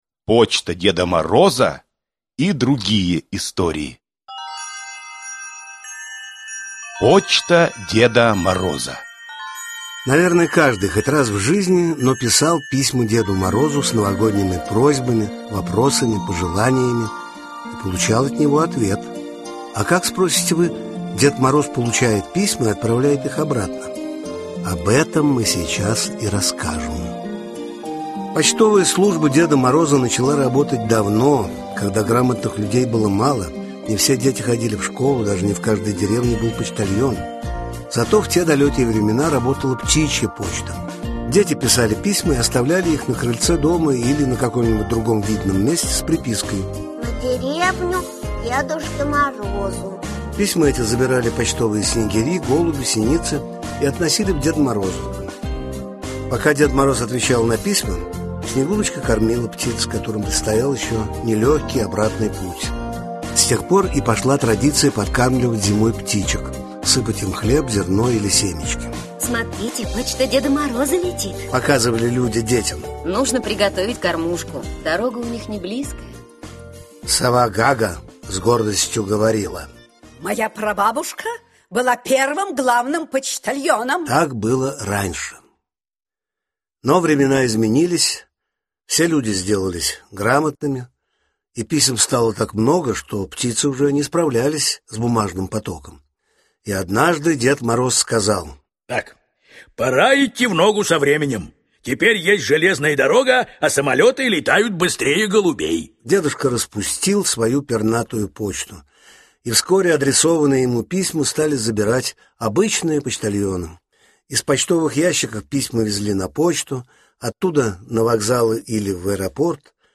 Аудиокнига Почта Деда Мороза (спектакль) | Библиотека аудиокниг